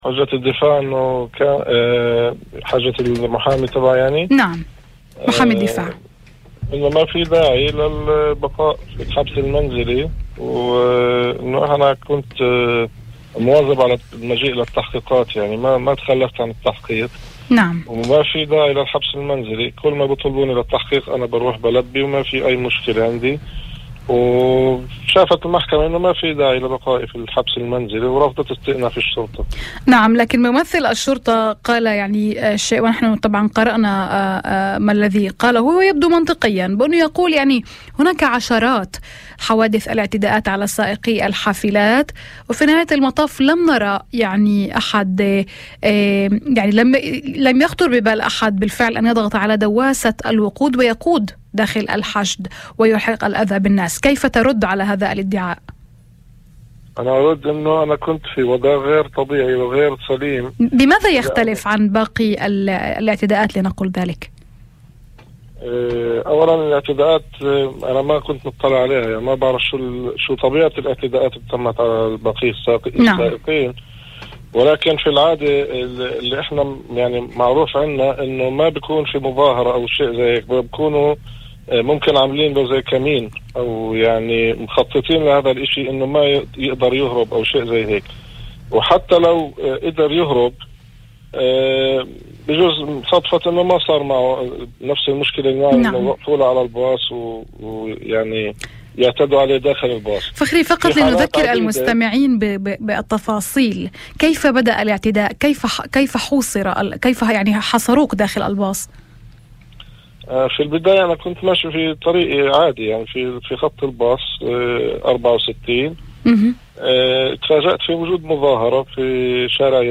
وفي مداخلة هاتفية لبرنامج "الظهيرة"، على إذاعة الشمس